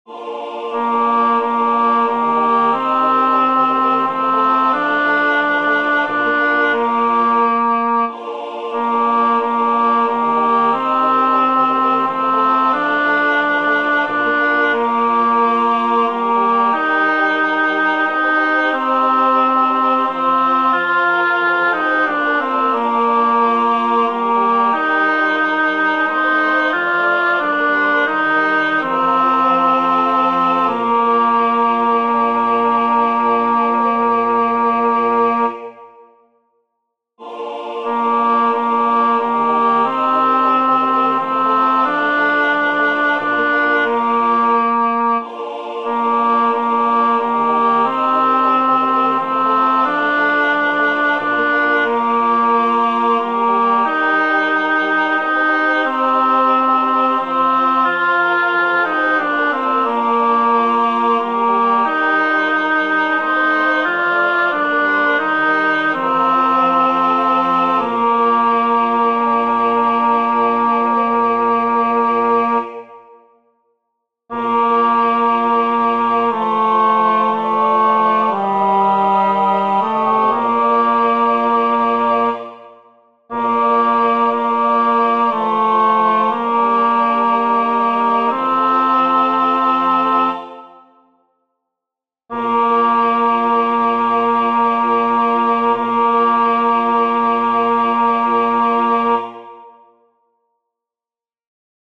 Para aprender la melodía os dejo los enlaces a los MIDIS según la primera versión que os expliqué más arriba, es decir, con el tenor a entrando a un tiempo de espera.
La melodía es muy sencilla y fácil de aprender.
ave_vera_virginitas-tenor.mp3